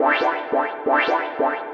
K-4 Stabs.wav